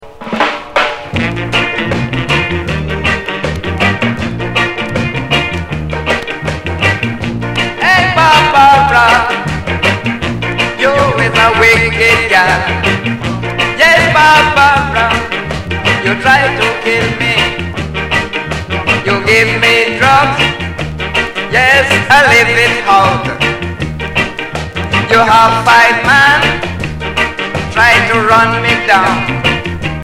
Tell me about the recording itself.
Notes: (VERY NOISY PRESSING)